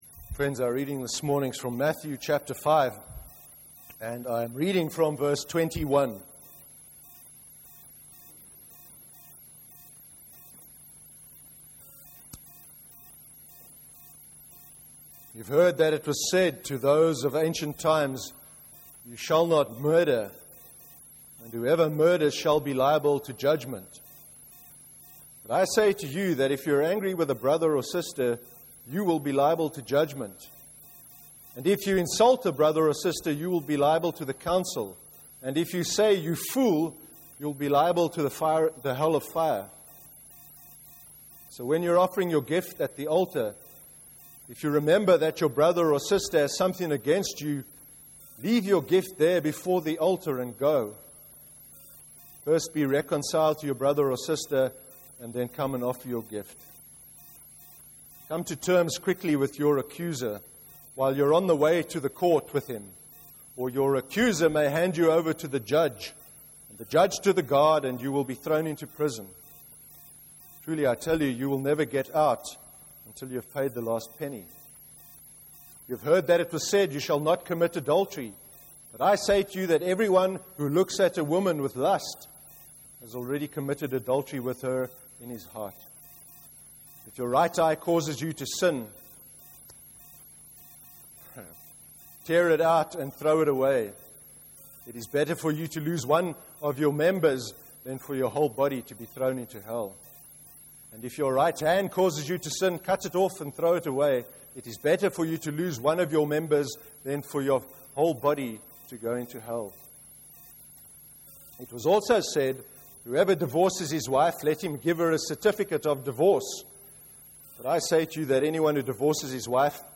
26/05/13 sermon – Laws that Jesus highlighted (Matthew 5:21-48)